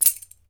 Index of /90_sSampleCDs/E-MU Producer Series Vol. 7 – Old World Instruments (CD 2)/Drums&Percussion/Bell Rattle
BEL RATTL02L.wav